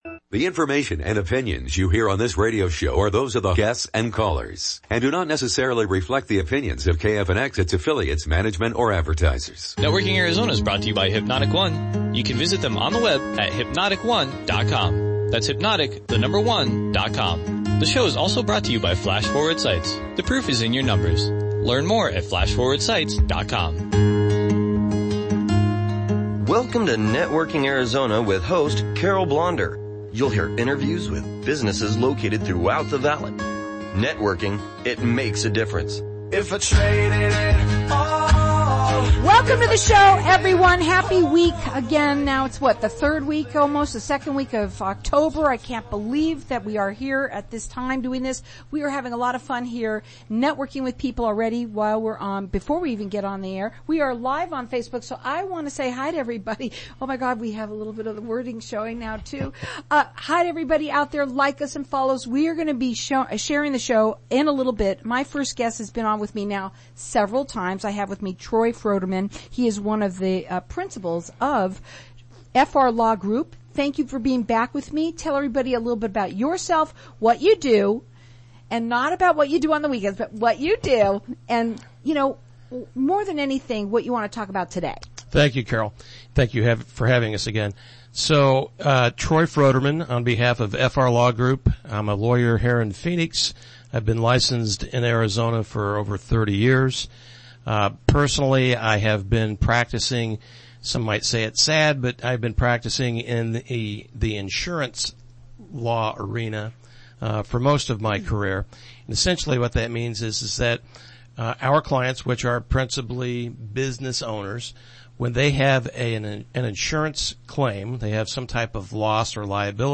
What happens and what to do when your insurance carrier denies your claims – Radio Guesting Oct 12, 2020